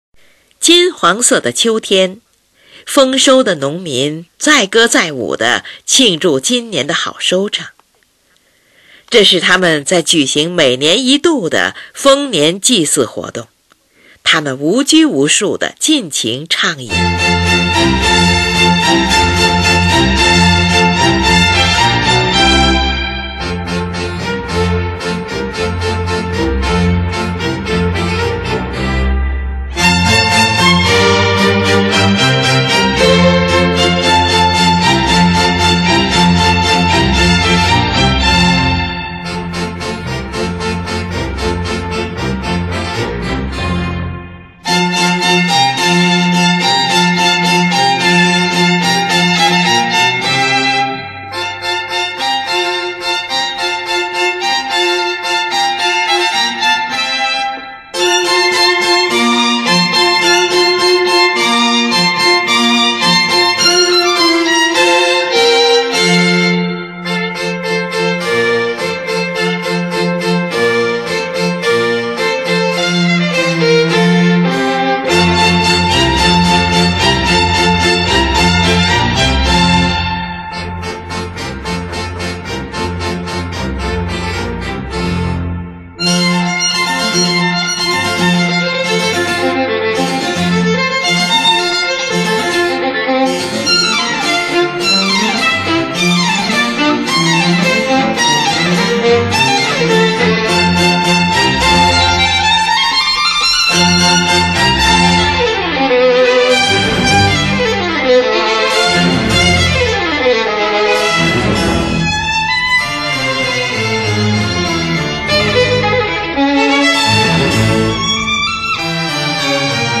小提琴协奏曲
《春》E大调、《夏》g小调、《秋》F大调、《冬》f小调。
《秋》描写的是收获季节中，农民们饮酒作乐、庆祝丰收的快活景象，这个乐章欢快而又活泼；
8. 很慢板，D小调。
第一、二主奏描写模仿的猎号，第三次主奏描写逃匿的野兽。